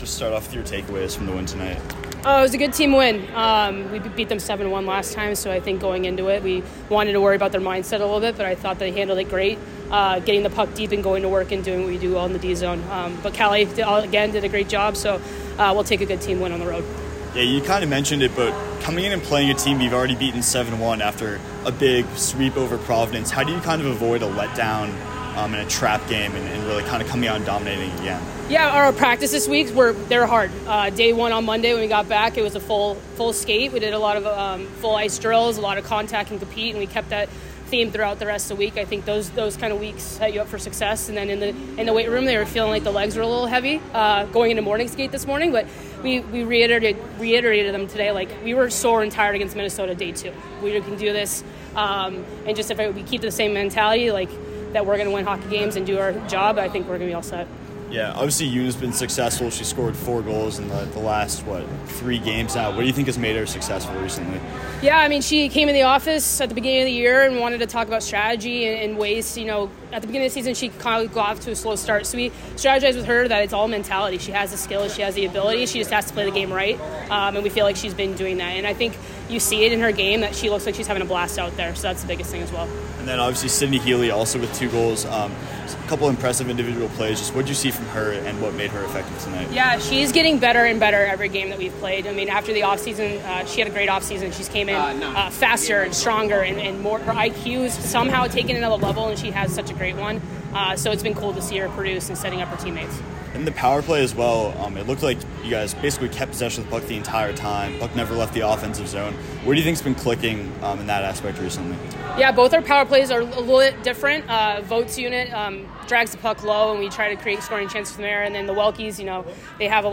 Merrimack Postgame Interview